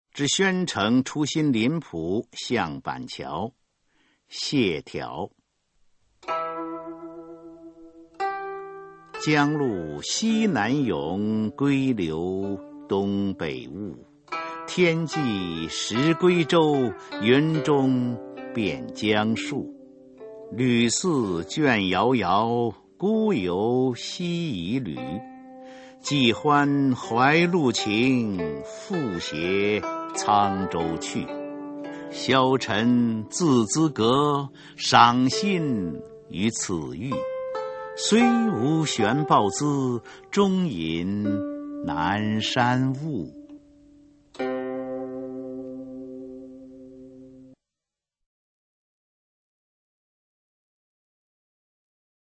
[魏晋诗词诵读]谢眺-之宣城出新林浦向板桥 古诗朗诵